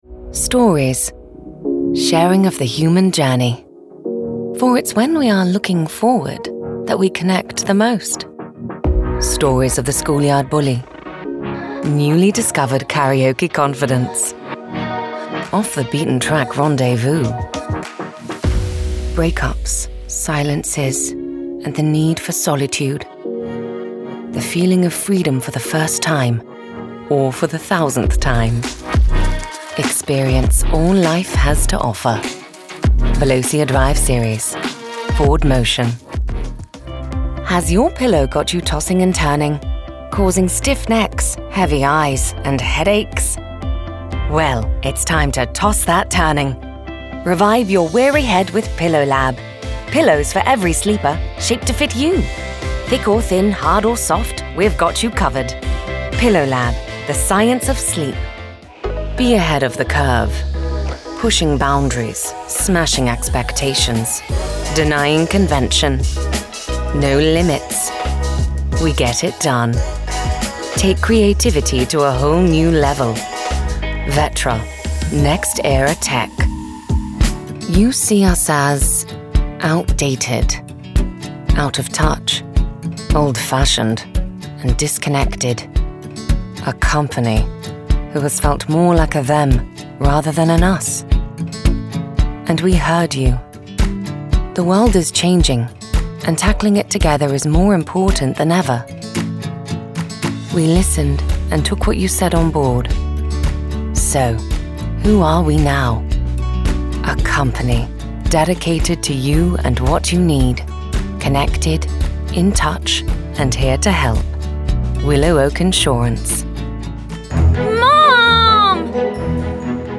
Demos and Samples
UK Commercial Demo